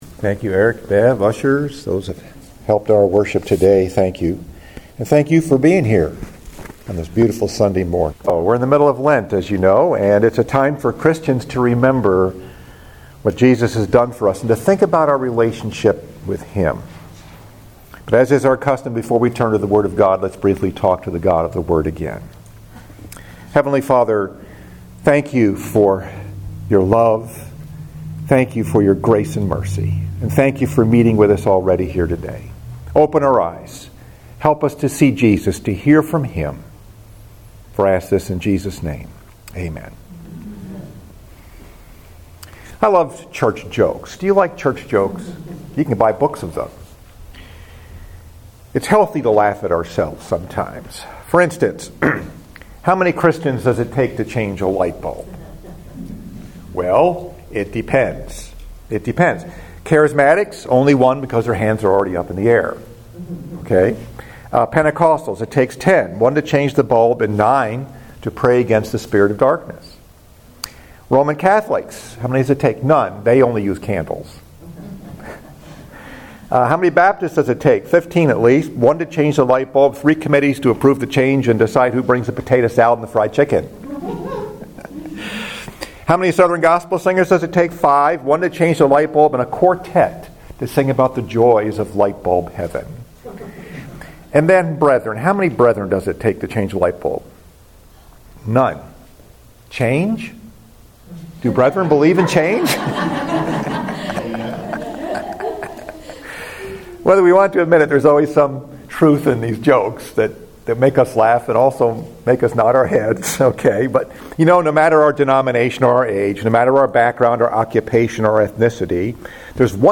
Message: “Just One Thing” Scripture: Luke 10:38-42 Third Sunday of Lent